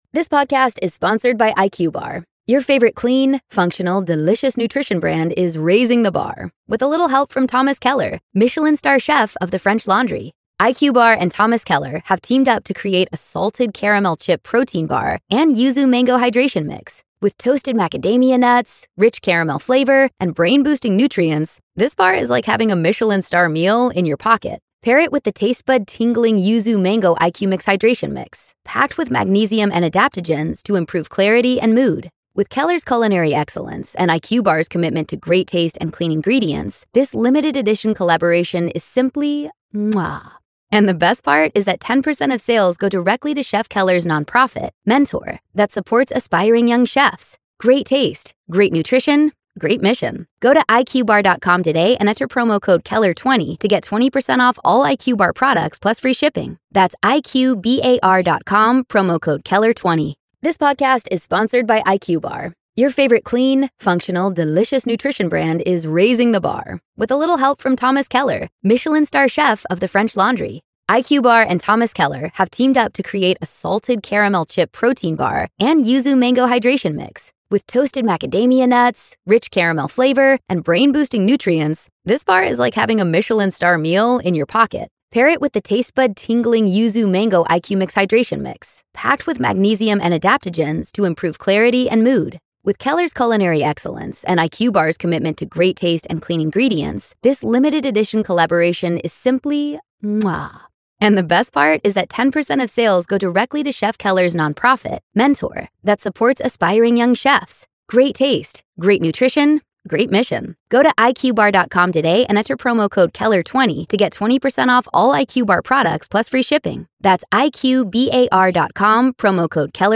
We hear calls from real people about their paranormal encounters.